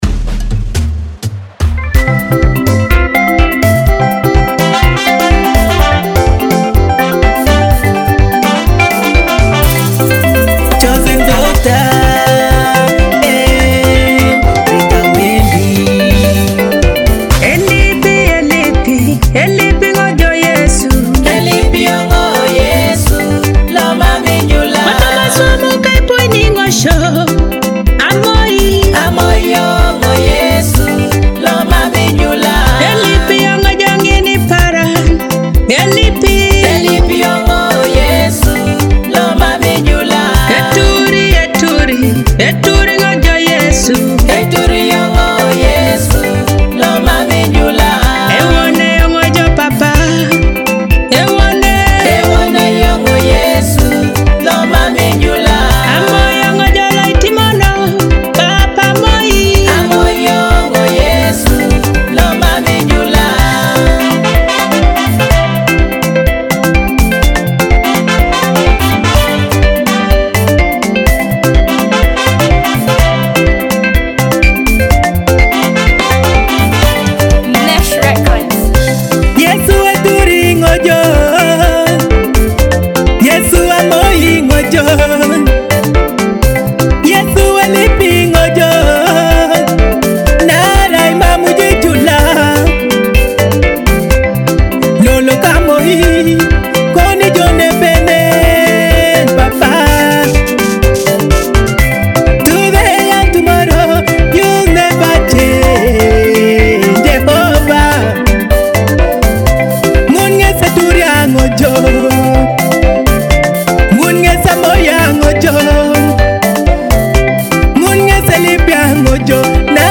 a vibrant Teso gospel song full of joy and gratitude.